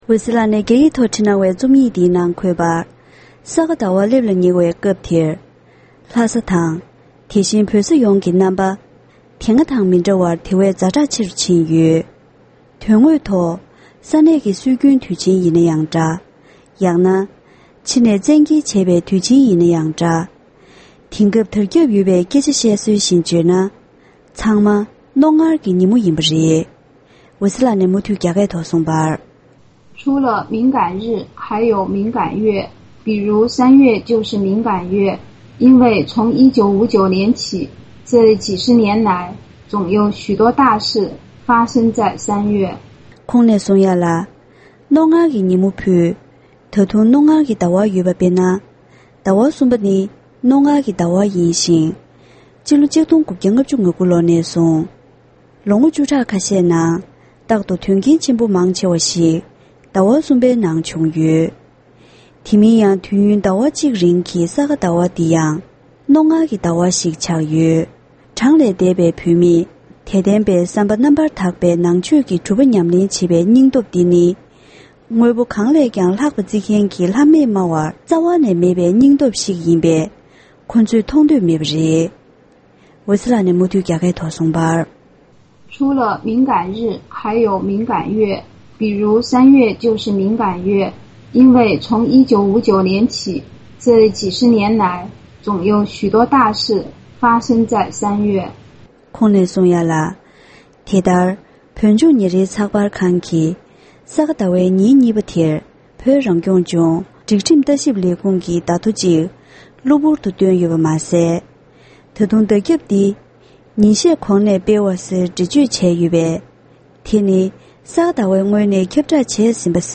ཕབ་བསྒྱུར་དང་སྙན་སྒྲོན་ཞུས་པར་གསན་རོགས་ཞུ༎